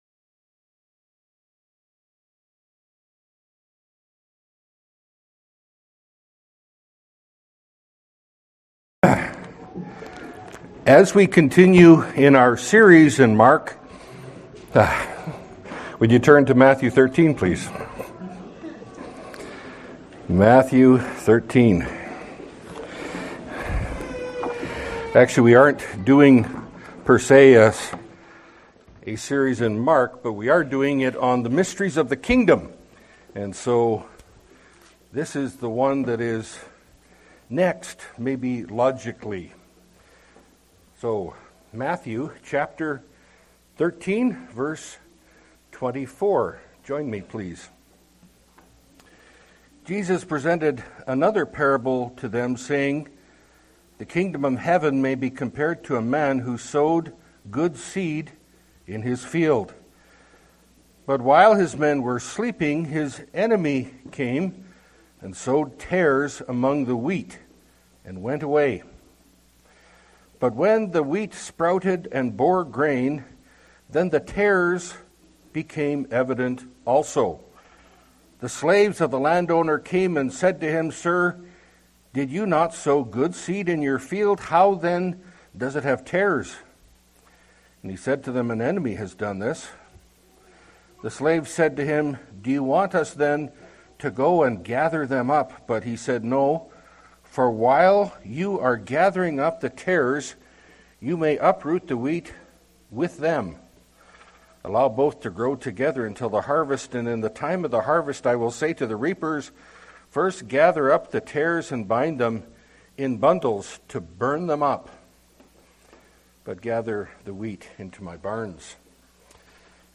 Category: Pulpit Sermons